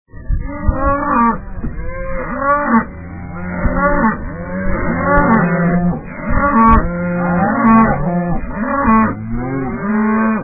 The herd is on the move!
Listen to them go!
Cows.mp3